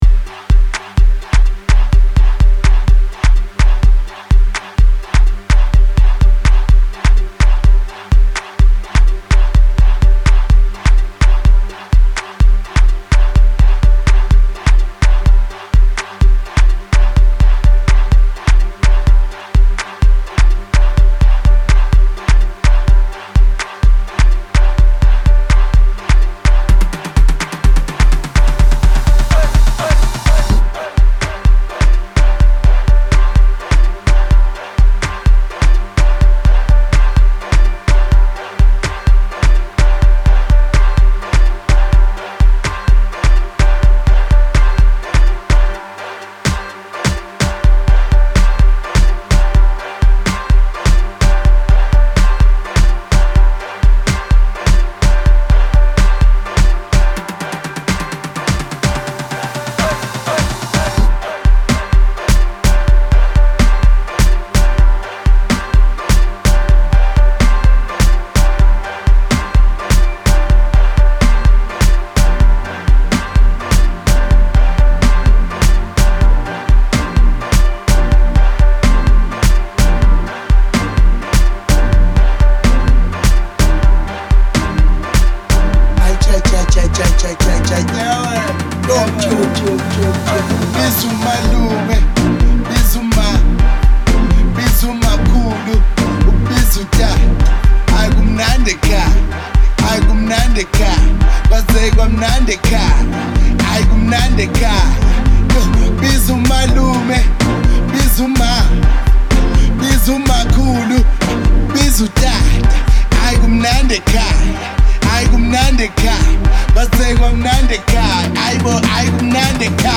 Categoria: Amapiano